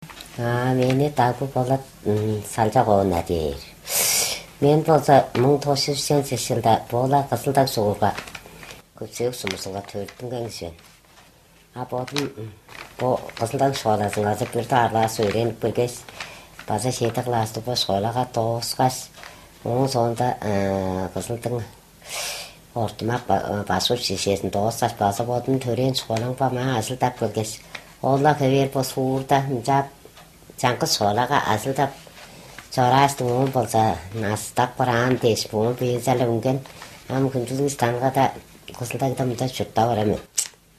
Here’s a recording in a mystery language.